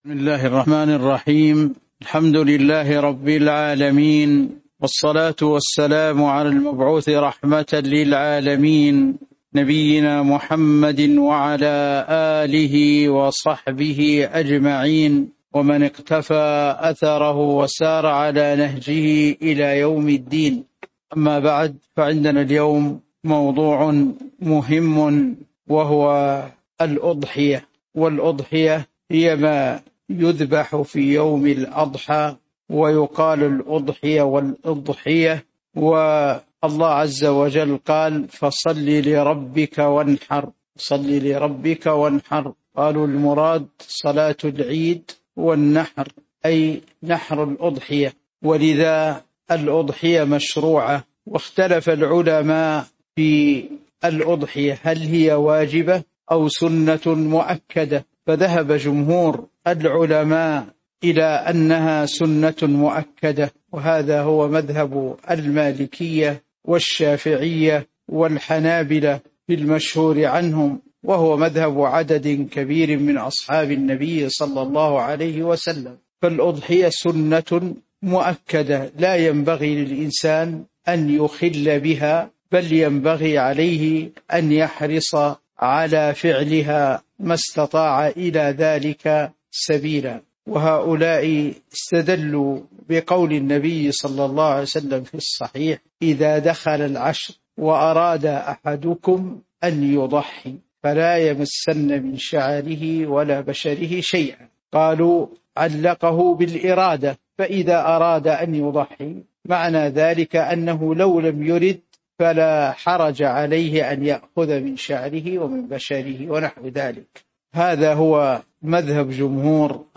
تاريخ النشر ٨ ذو الحجة ١٤٤٤ هـ المكان: المسجد النبوي الشيخ